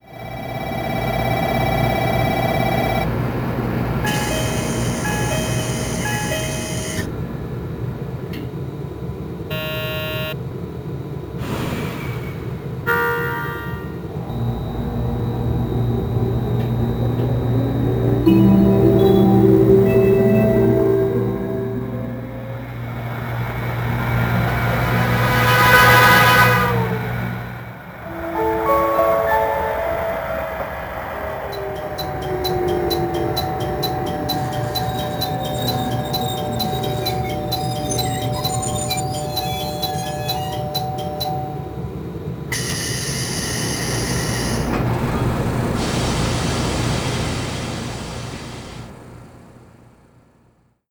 電笛／電笛＋空笛／警笛（ドップラー）
ドア開閉音／ドアチャイム
発車／接近ベル
車内ブザー／車内チャイム1・2
②　JR発足当時まだ主流だった、直流電動機と中空軸平行カルダン駆動による787系の走行音を再現
③　ファンクションボタンを押すことにより、JR九州らしさを発散する警笛が楽しめるほか、運転士目線で聴く本来の音程に加え、耳馴染みのある高速接近時の音色も選択可能。